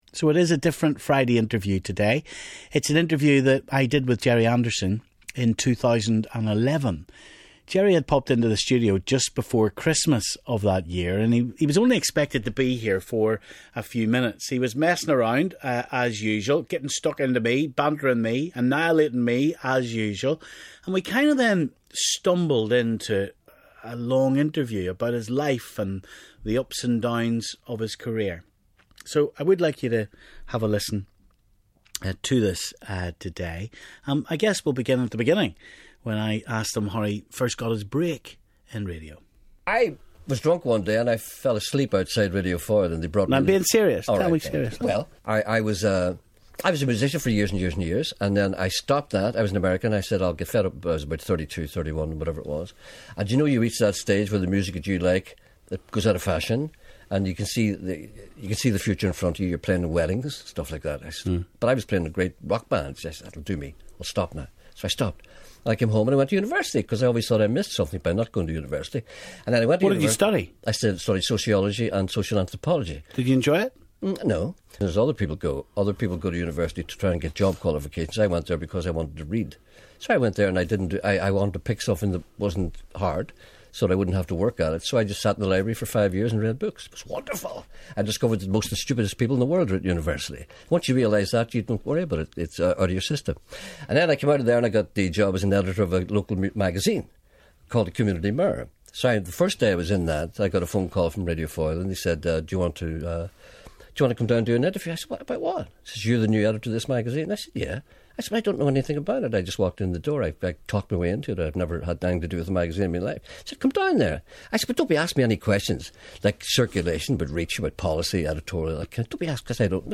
Big interview - Stephen in conversation with Gerry Anderson, Christmas 2011
Gerry had popped into our studio just before Christmas in 2011.